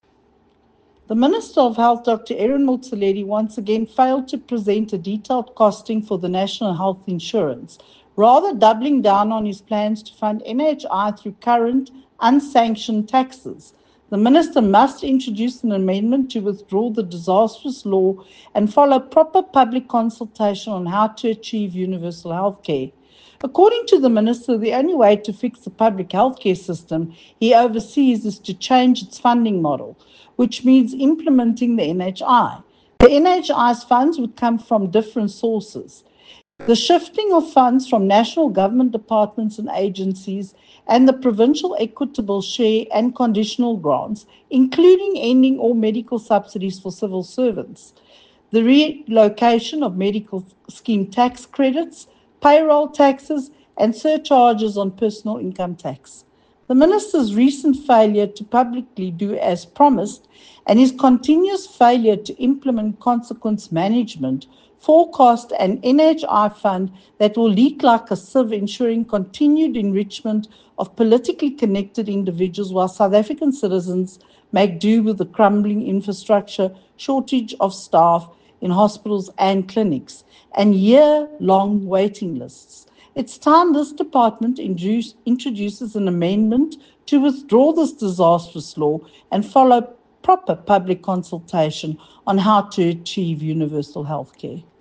Soundbite by Michéle Clarke MP.